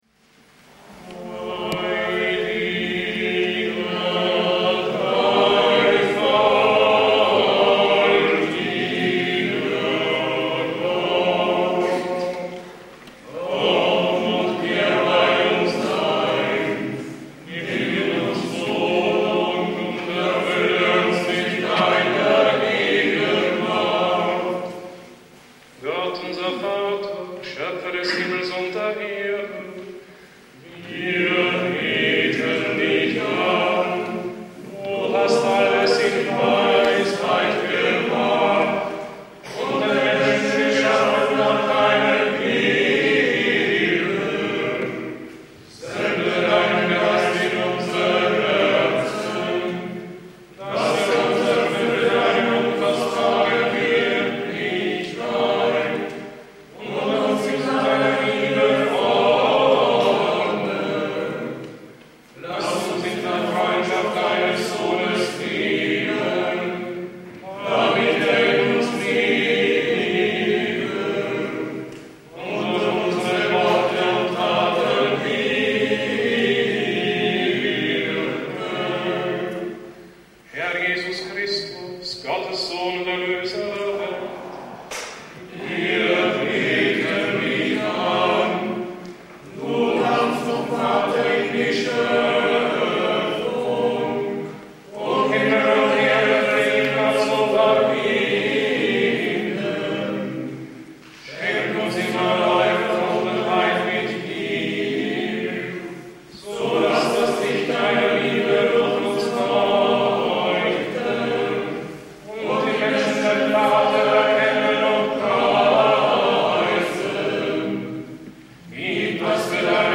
Für den Gebetsrahmen wählte der Gründer wegen seiner Ausdrucksstärke und seiner Erlernbarkeit den Kiewer Choral, wie er der liturgischen Praxis im bayerischen Kloster Niederaltaich (byzantinische Dekanie) zu Grunde liegt, hier mit strenger Terzparallele und Funktionsbass.